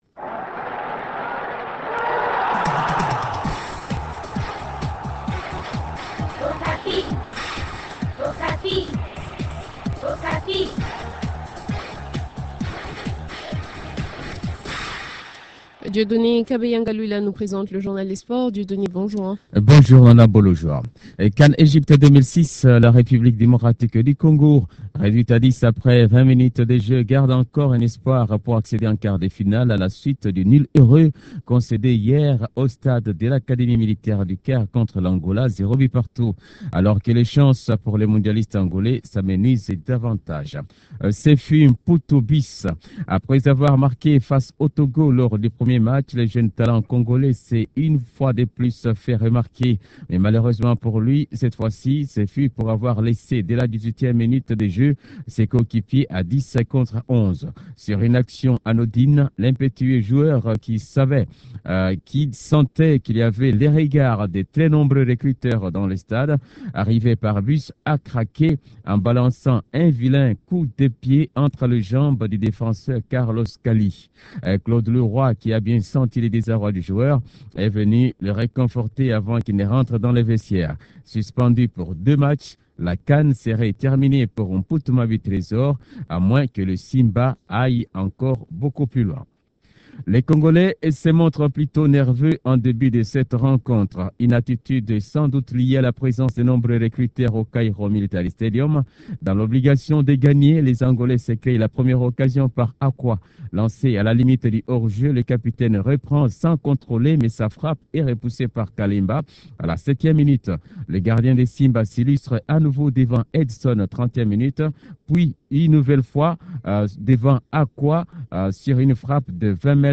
a premené son micro dans les différentes rues de Bunia juste après le match.